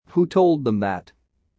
【ややスロー・スピード】
t,d,k,g,p,b:子音の飲み込み